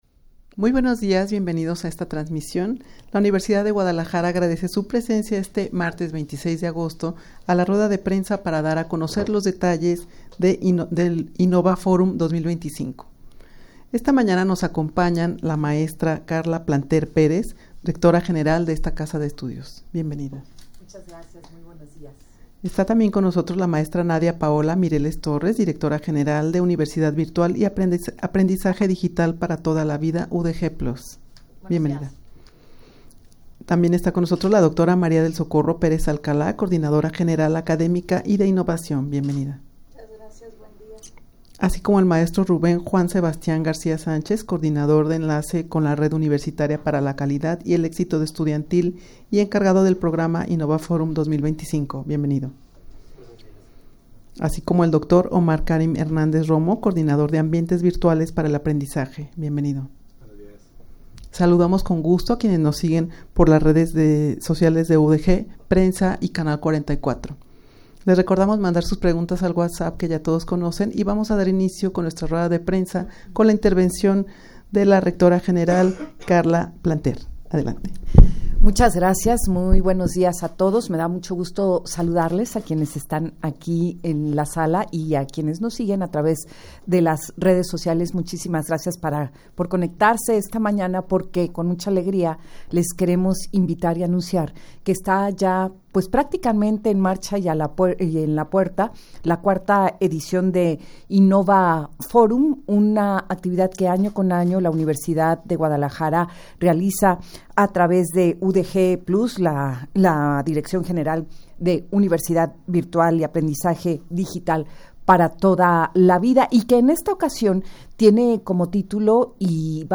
Audio de la Rueda de Prensa
rueda-de-prensa-para-dar-a-conocer-los-detalles-de-innova-forum-2025.mp3